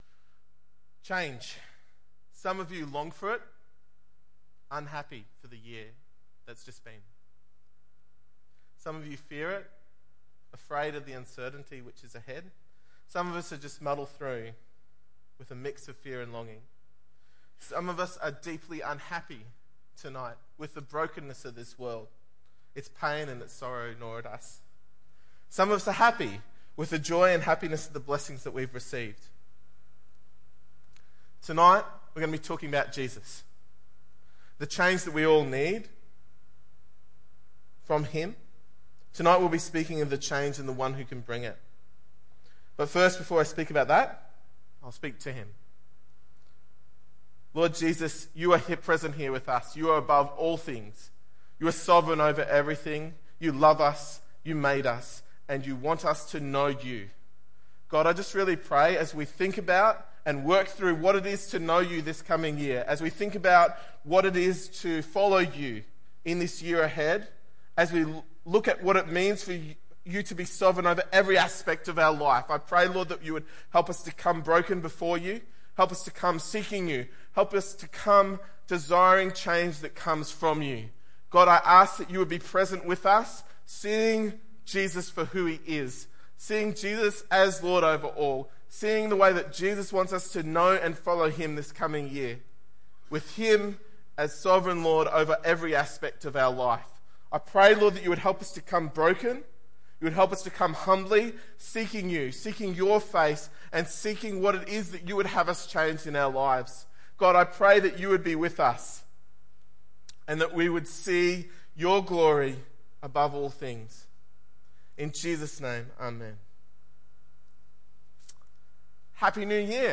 2016 Guest Speaker Individual Message Colossians https